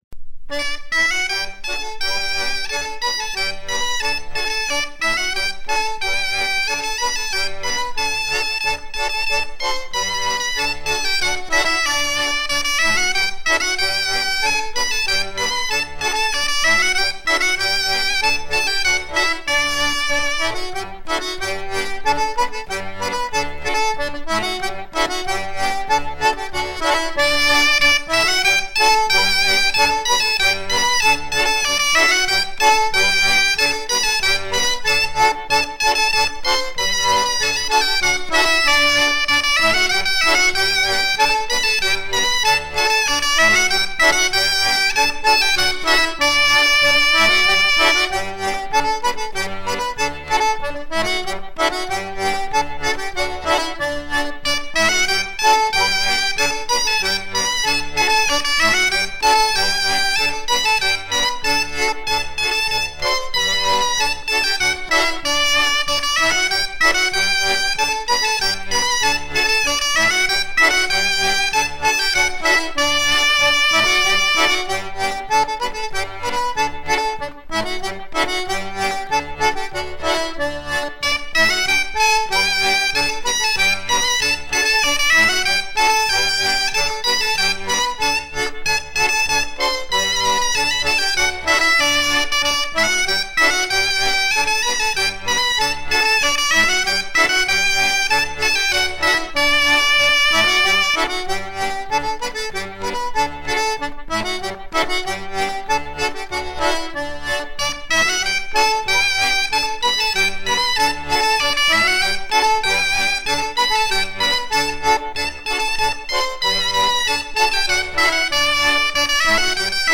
Hanter dro
recueilli vers 1980 à Sarzeau
danse : hanter-dro
Pièce musicale éditée